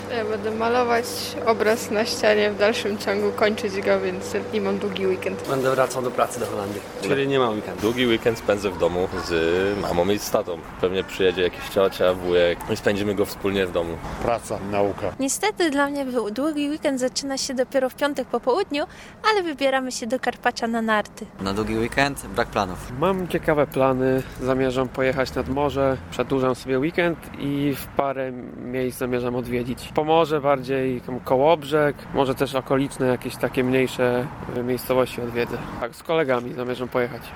Jak spędzamy długi weekend? (sonda)
Zapytaliśmy zielonogórzan, jak planują spędzić ten czas: